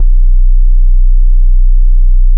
TEST BASS -R.wav